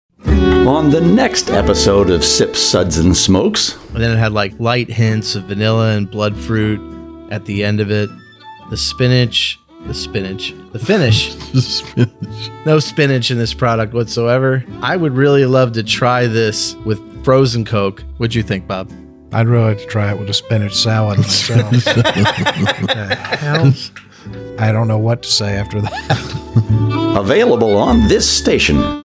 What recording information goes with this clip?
192kbps Mono